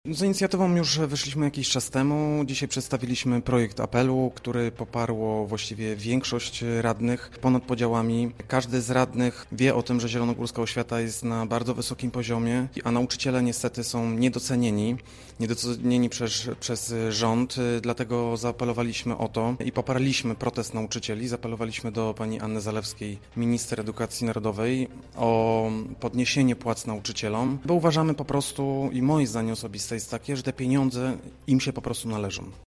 Projekt apelu przedstawił przewodniczący Komisji Edukacji i Wychowania Grzegorz Hryniewicz z klubu Zielona Razem: